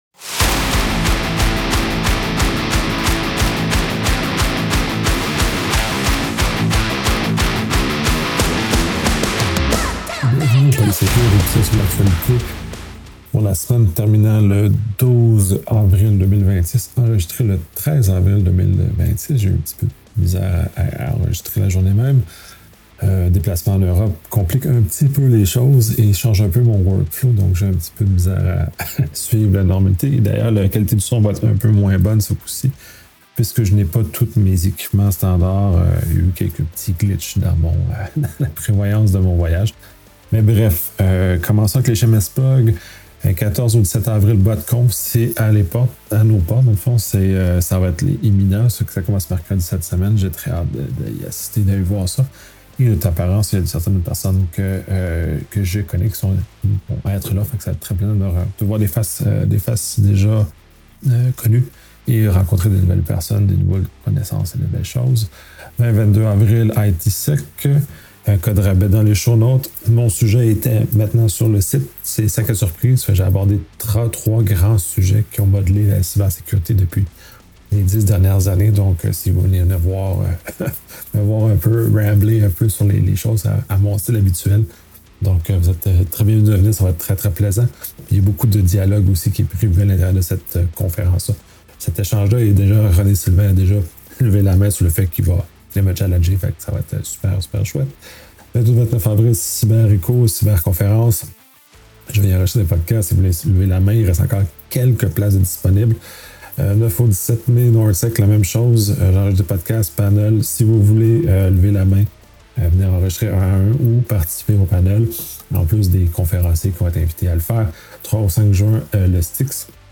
Je suis en déplacement et je n’ai pas tous mes équipements habituels. Je n’ai pas encore trouvé l’équilibre entre la frugalité des choses que je mets dans ma valise et le maintien de la qualité de l’enregistrement.